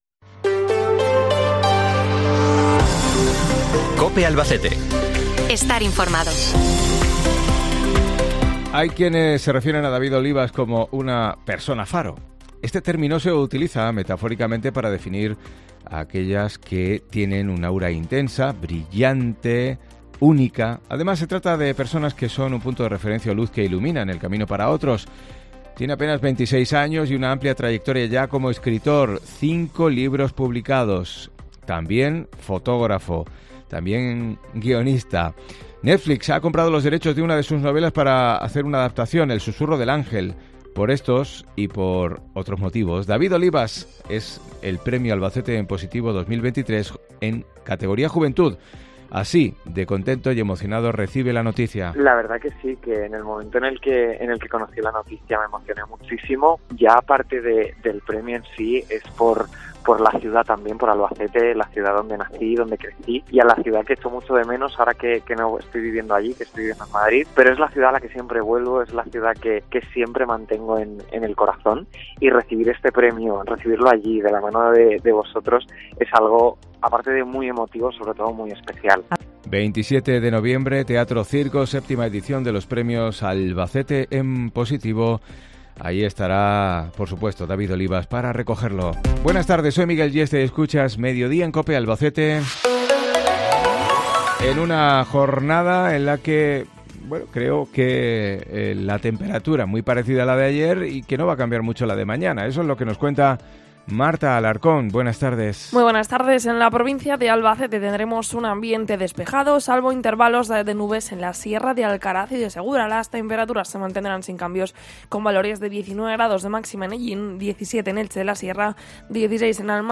Escucha aquí las noticias de tu provincia en COPE Albacete